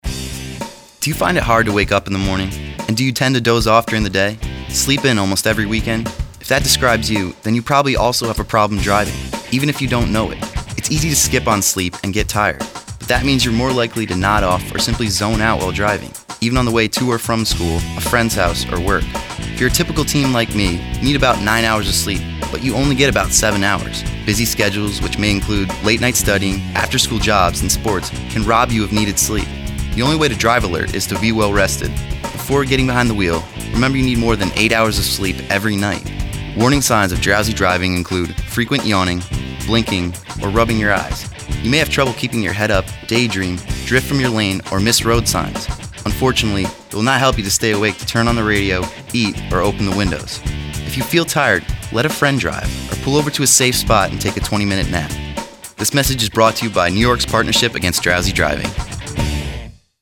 High School Student :60 Radio PSA.